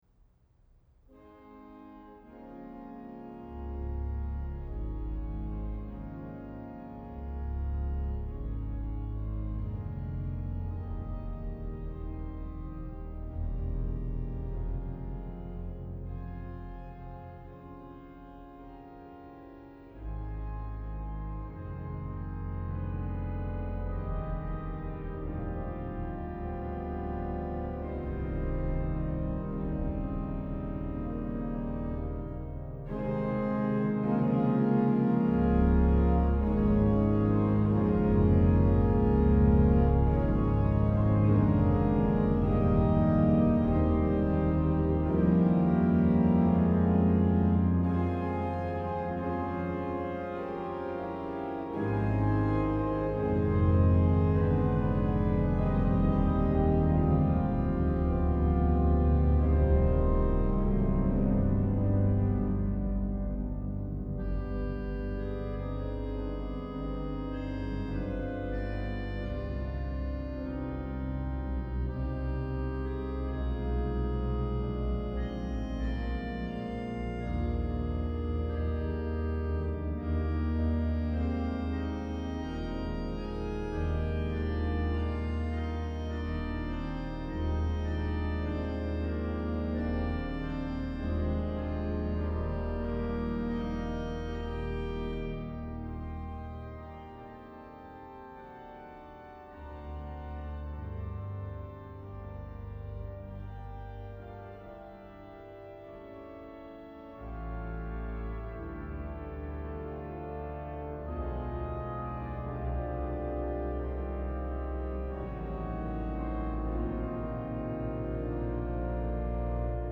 Die Hauptorgel im Dom zu Speyer
Stilistisch wird so eine Brücke zwischen Barock und Romantik gebaut.